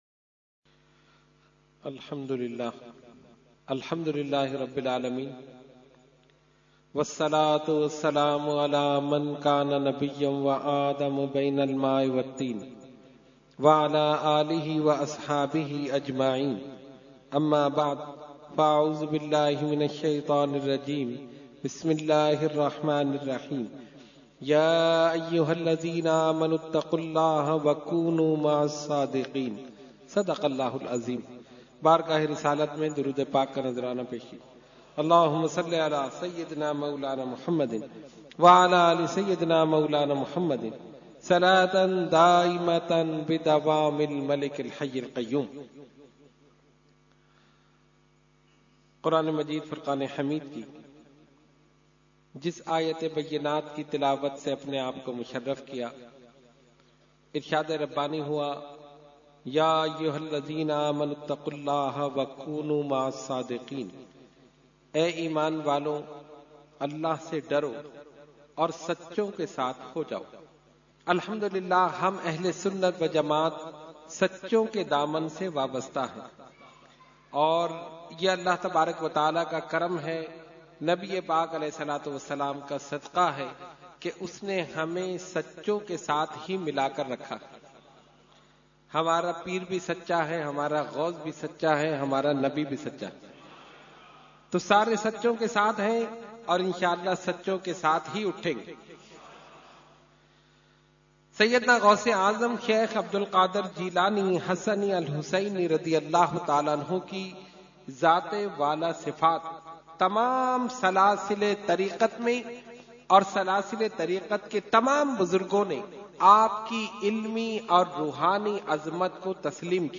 Category : Speech | Language : UrduEvent : 11veen Shareef 2018-2